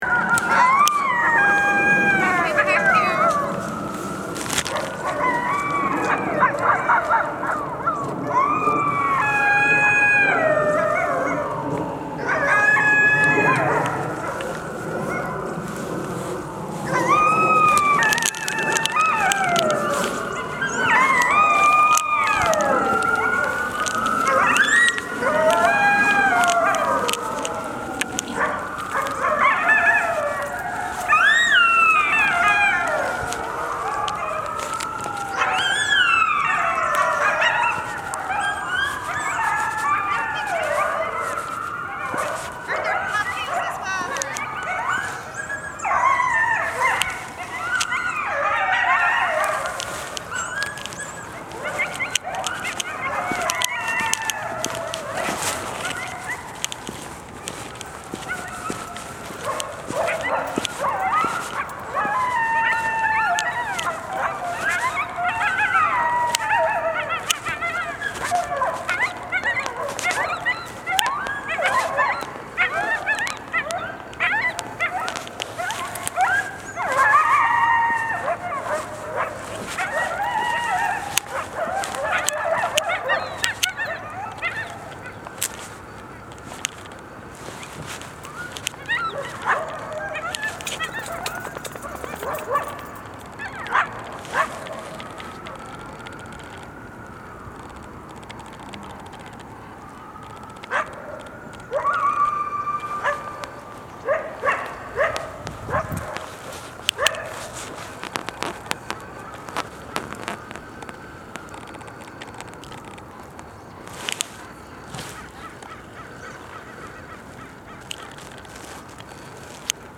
On this day, I found her already hunting as I entered the park. When a siren sounded, she yipped in response, and then the male, who was hidden in the bushes, joined the chorus. This is the full length of the howl, and a normal one for these two individuals. It’s just the two of them.